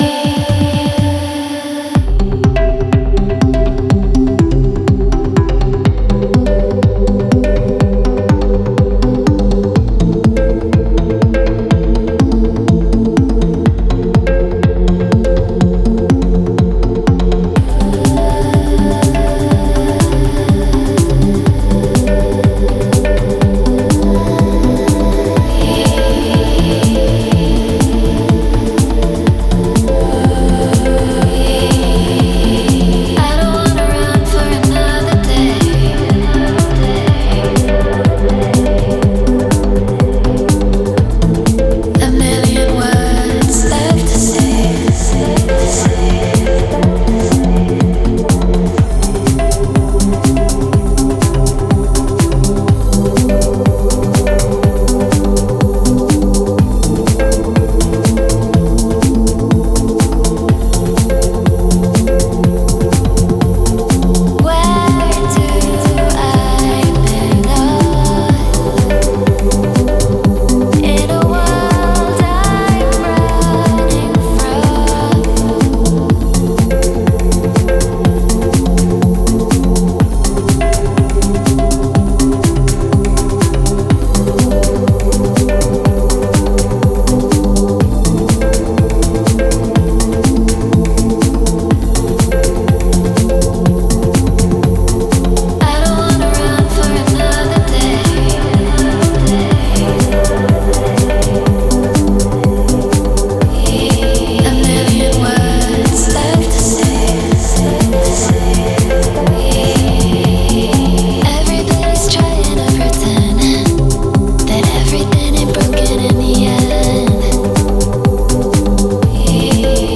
8B - 123 Melodic House